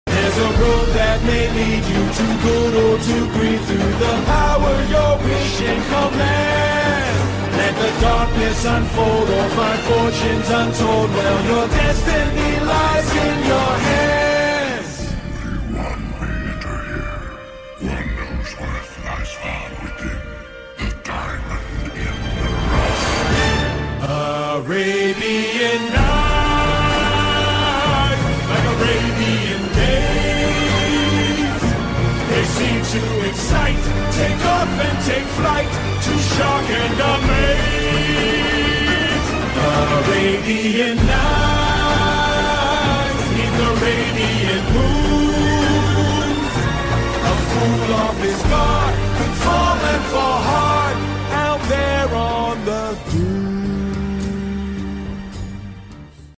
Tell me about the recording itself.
idk why the quality sucks